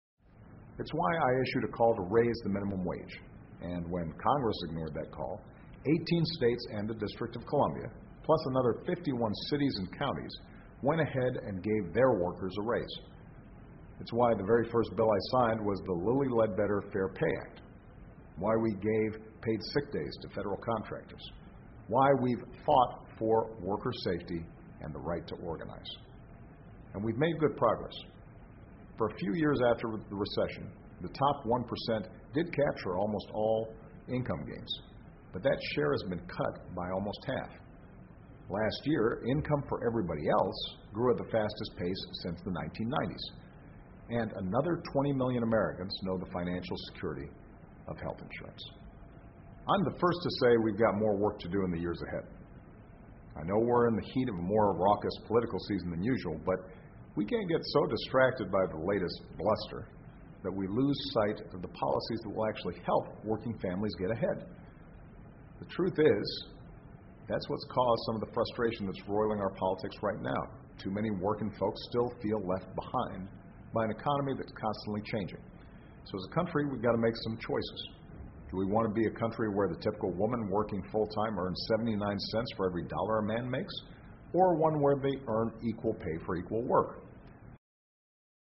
奥巴马每周电视讲话：总统阐释劳工节传承的财富（02） 听力文件下载—在线英语听力室